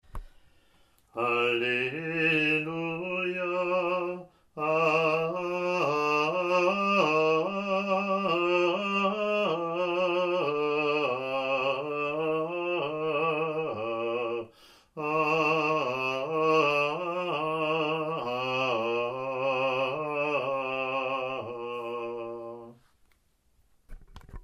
Alleluia Acclamation